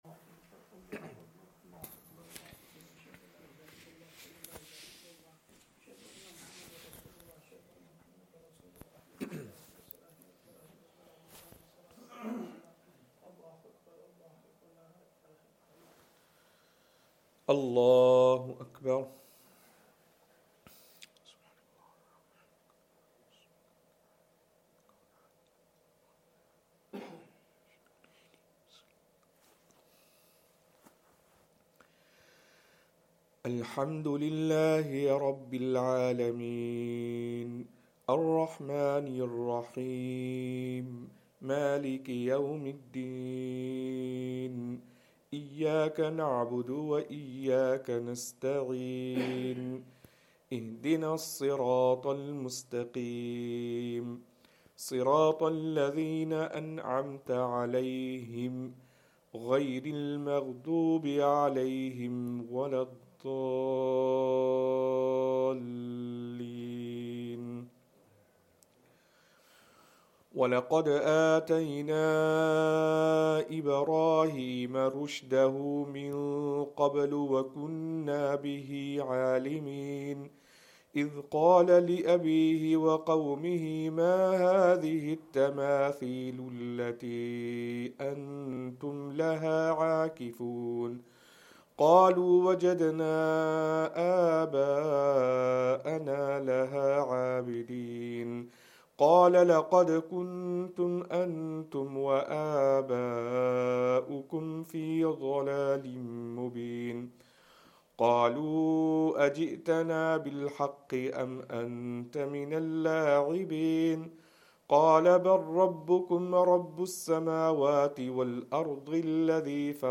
Fajr
Madni Masjid, Langside Road, Glasgow